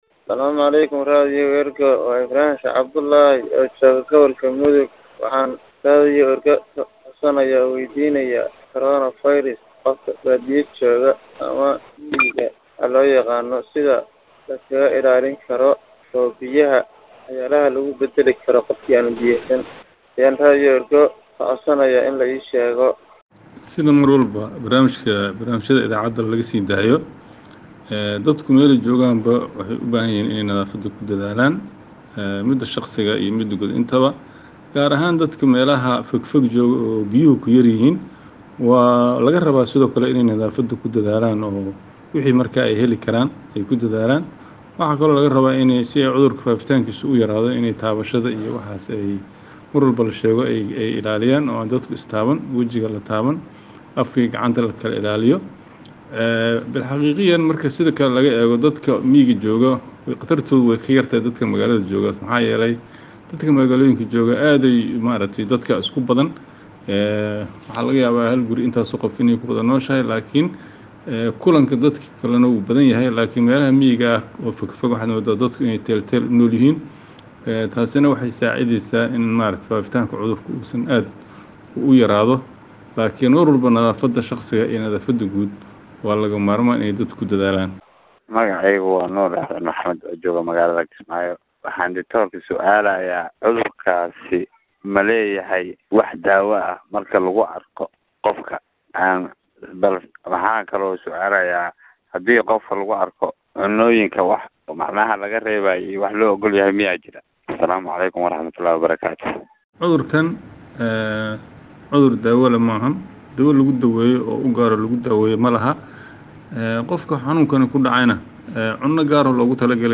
Health expert answers listeners’ questions on COVID 19 (1)
Radio Ergo provides Somali humanitarian news gathered from its correspondents across the country for radio broadcast and website publication.